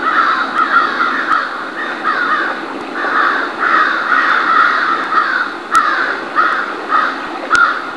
American Crow Corvus brachyrhynchos (Corvidae)
Call
crowusgs.wav